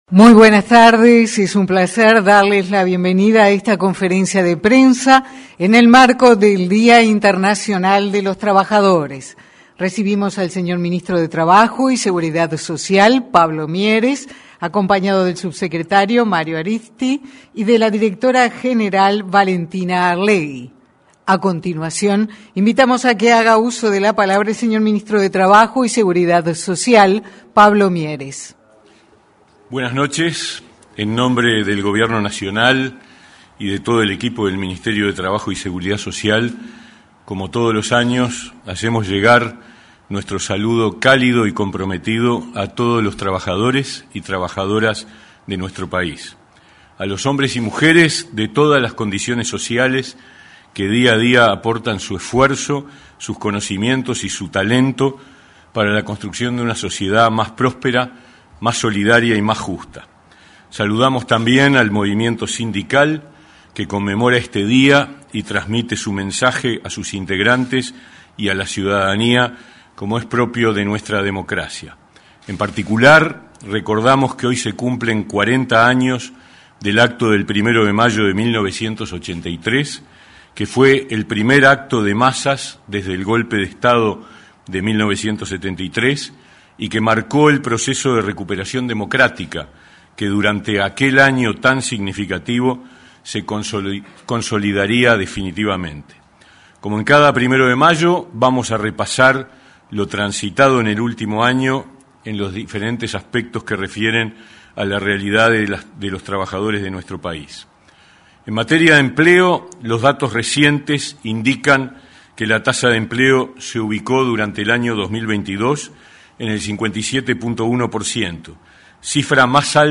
Ministerio de Trabajo brindó conferencia de prensa con motivo del Día Internacional de los Trabajadores
Ministerio de Trabajo brindó conferencia de prensa con motivo del Día Internacional de los Trabajadores 01/05/2023 Compartir Facebook X Copiar enlace WhatsApp LinkedIn El ministro de Trabajo y Seguridad Social, Pablo Mieres, brindó, junto con el subsecretario de la cartera, Mario Arizti, y la directora general, Valentina Arlegui, una conferencia de prensa, con motivo del Día Internacional de los Trabajadores.